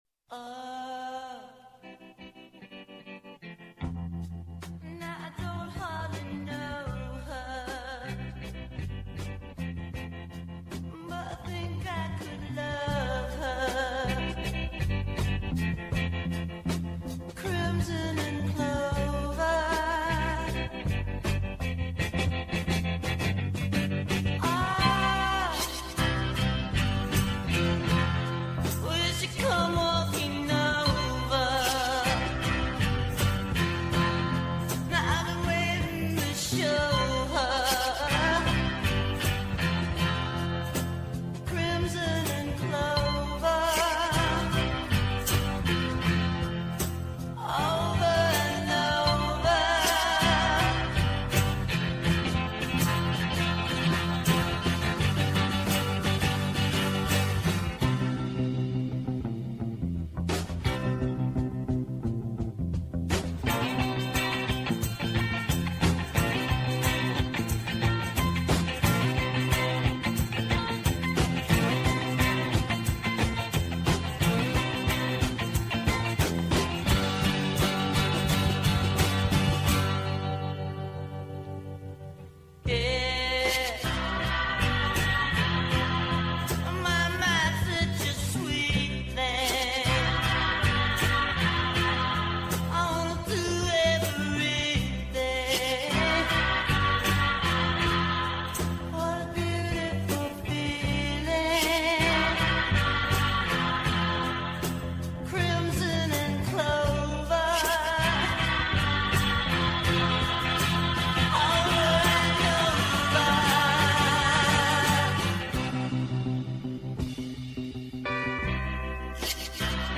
Σήμερα καλεσμένοι συμμετέχοντες στο Παγκόσμιο Συνέδριο Πληροφορικής στη Μαλαισία όπου εκπροσωπήθηκε και βραβεύτηκε με οκτώ συμμετοχές ο Σύνδεσμος Επιχειρήσεων Πληροφορικής και Επικοινωνιών Ελλάδας (ΣΕΠΕ) :